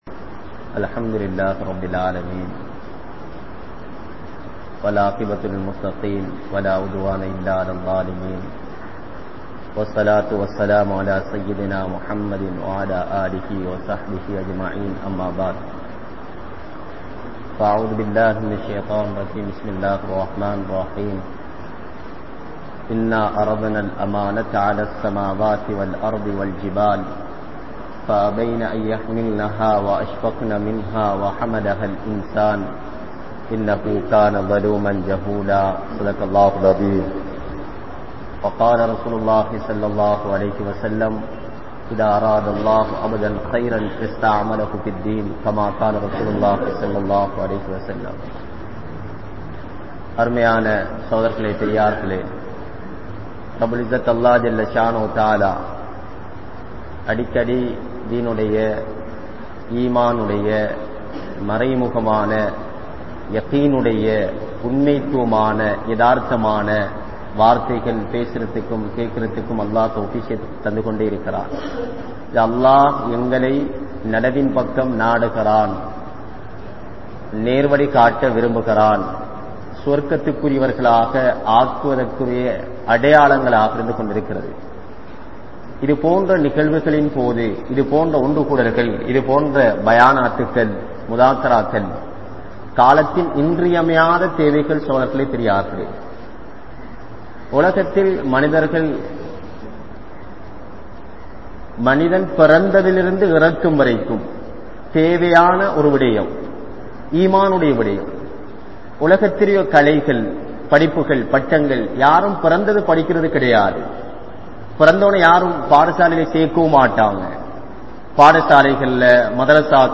Dheen Oru Amaanitham (தீண் ஒரு அமானிதம்) | Audio Bayans | All Ceylon Muslim Youth Community | Addalaichenai
Kabeer Jumua Masjith